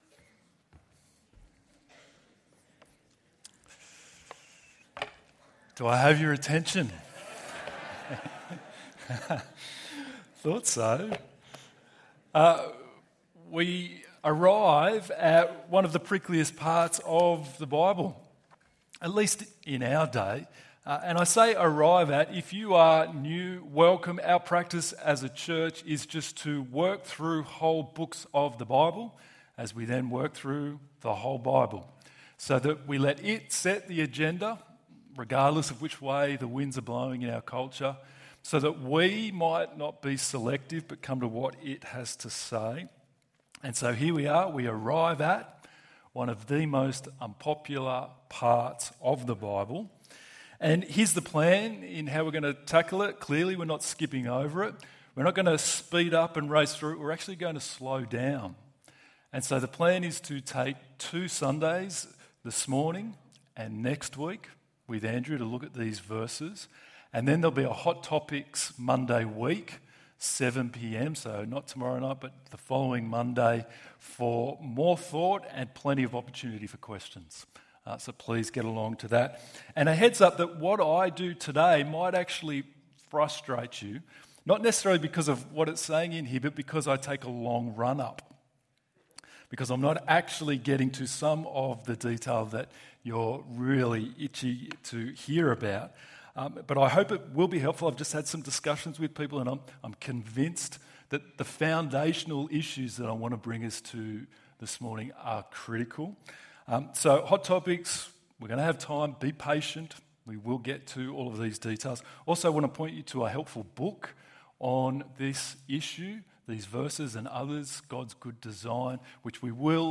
The equality of Men and Women ~ EV Church Sermons Podcast